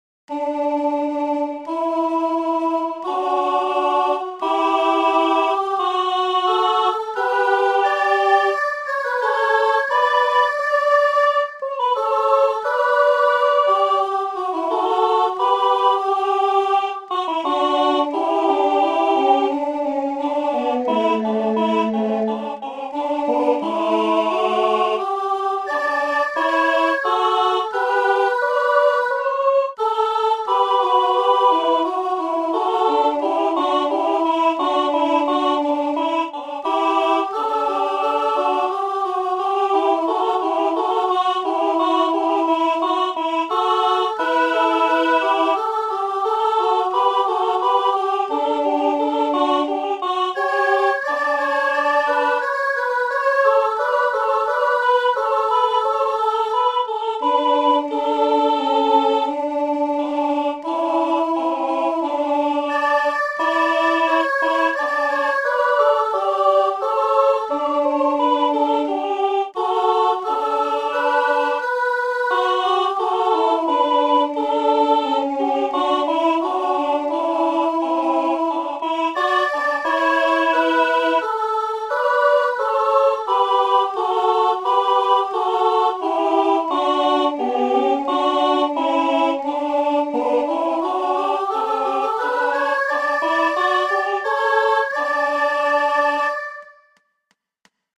chant
It’s just the two lines of the canon.